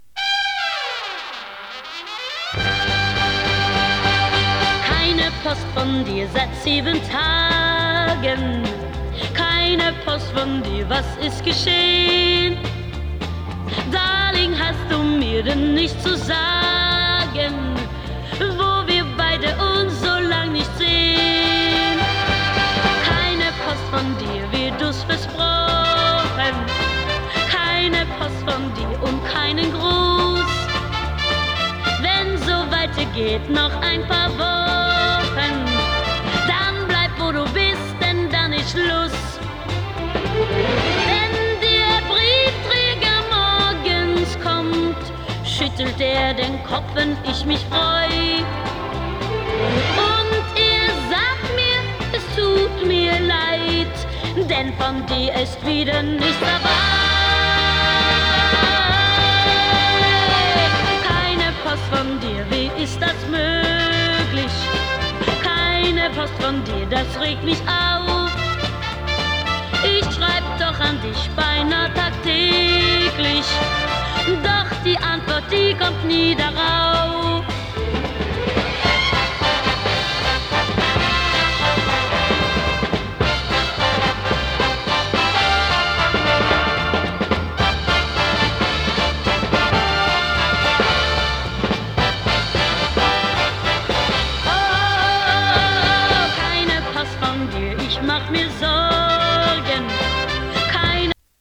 グルーヴィーソフトロック